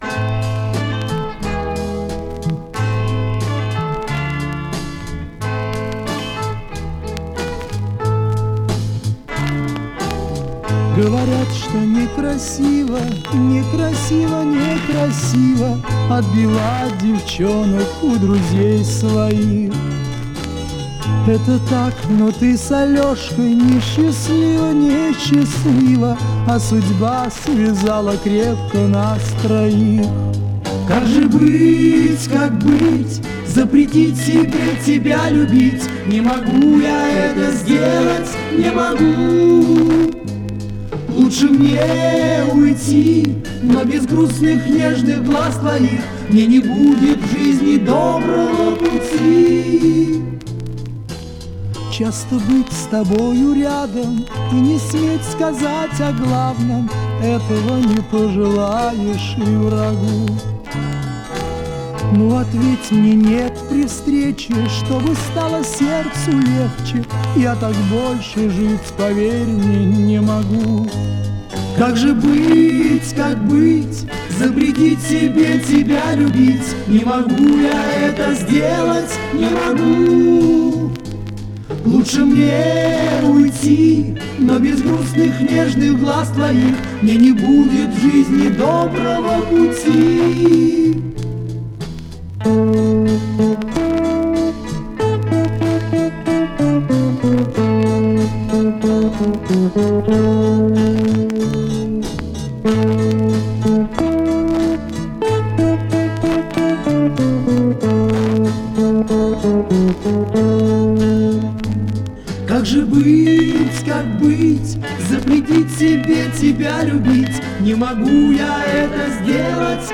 VESYOLYE REBIATA VOCAL-INSTRUMENTAL ENSEMBLE
(7" flexi EP)
Mono or Stereo: Mono